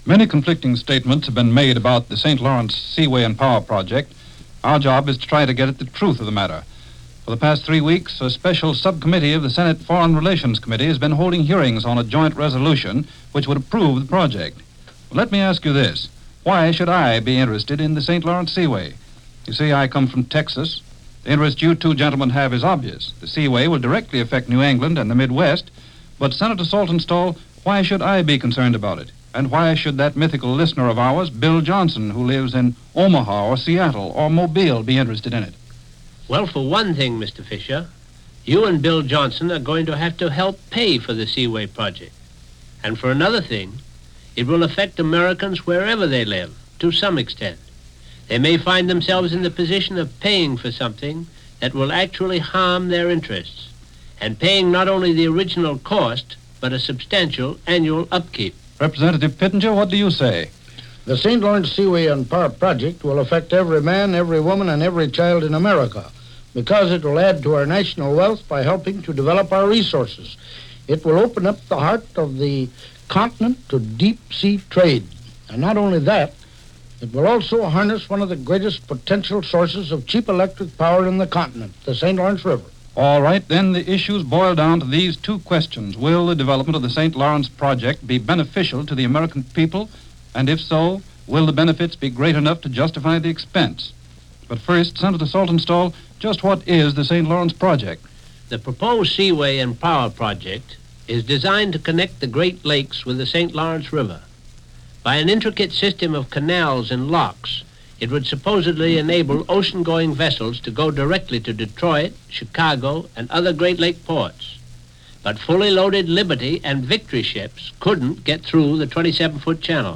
A Seaway Named St. Lawrence - Proposals For A Waterway - 1946 - Discussion With Sen. Leverett Saltonstall And Rep. William Pittenger - Past Daily Reference